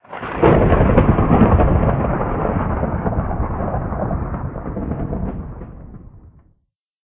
thunder1.mp3